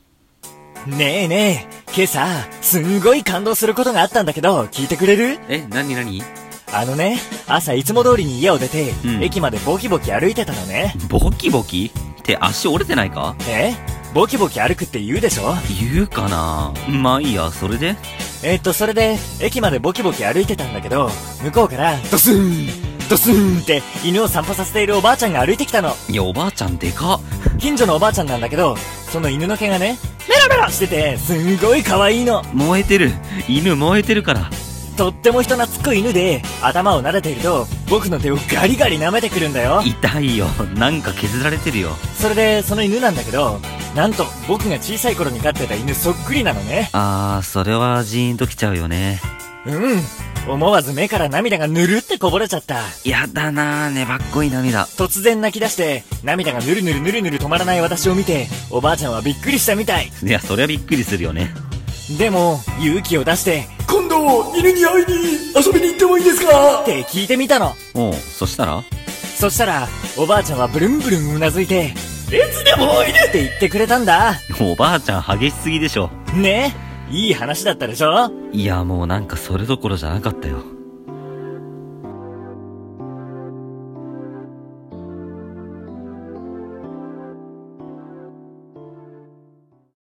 【声劇コラボ用】擬音がおかしい人